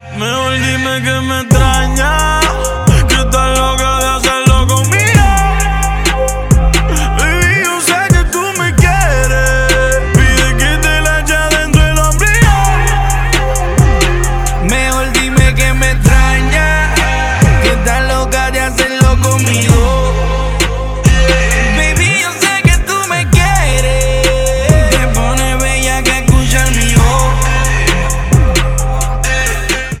Рингтоны мужской голос
Рэп